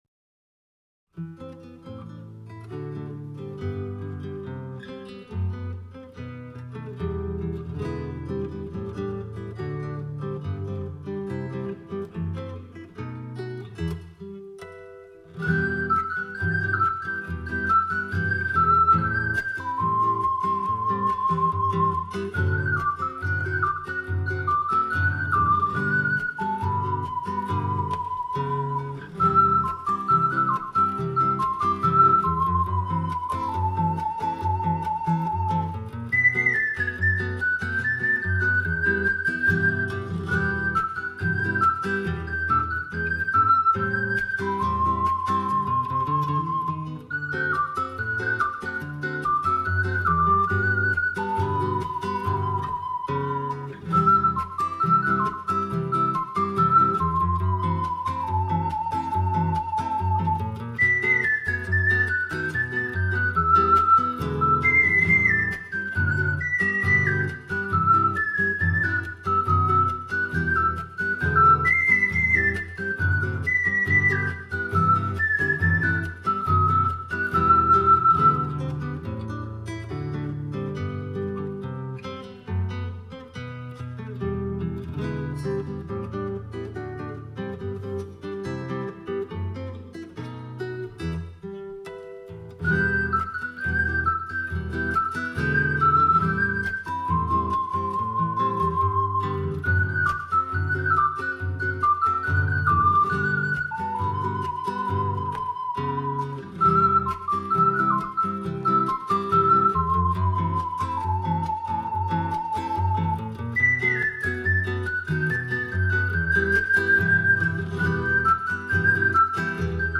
＊試奏は、ろくに練習もせず雑に録音・編集したので、参考程度と思ってください。